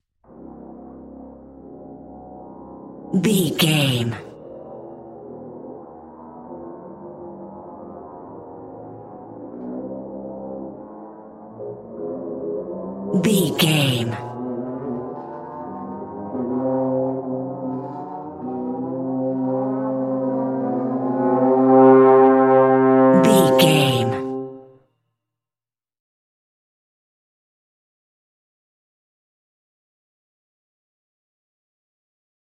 In-crescendo
Aeolian/Minor
tension
ominous
suspense
eerie
driving
brass
synths
atmospheres